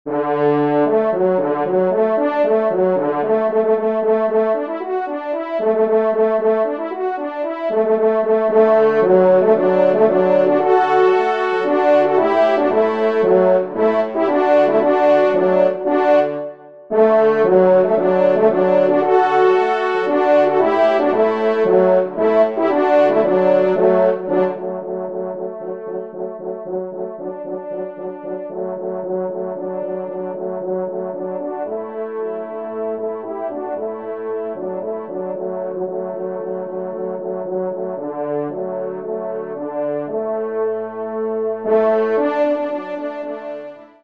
Genre :  Divertissement pour Trompes ou Cors en Ré
3e Trompe